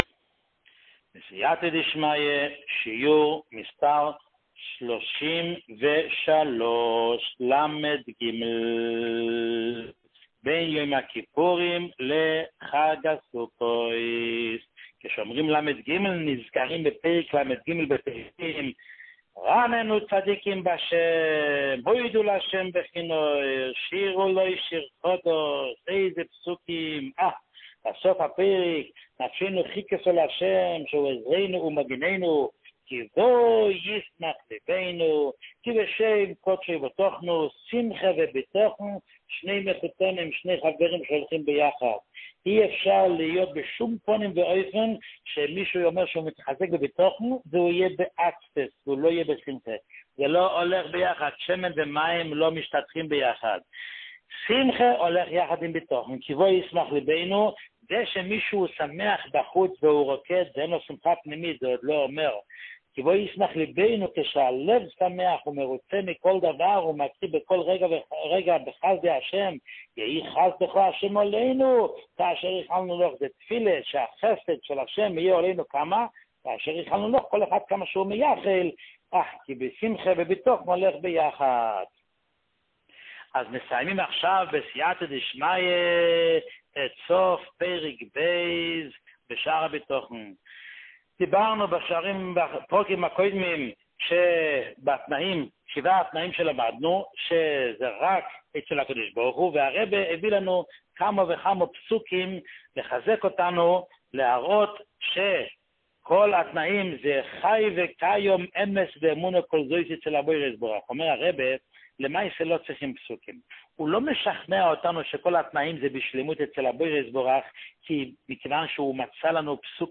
שיעור 33